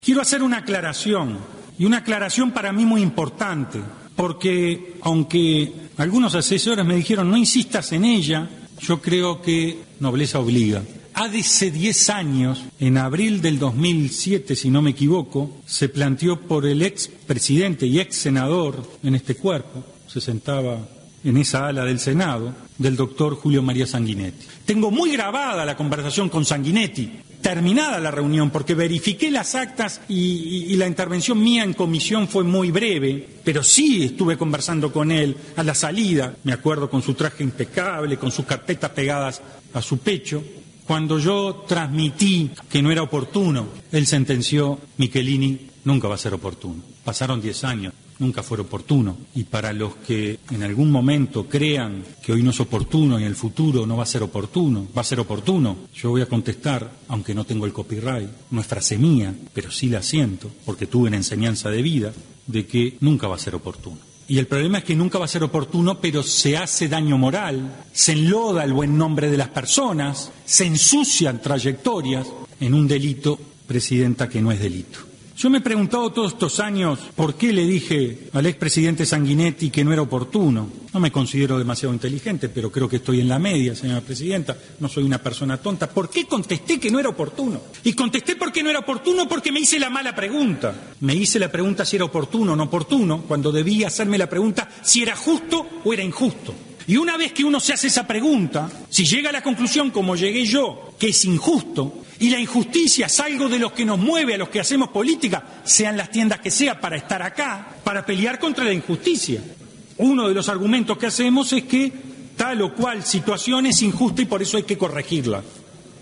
El miembro informante del proyecto, Rafael Michelini explicó porque en el 2007 pensaba de una manera y ahora de otra.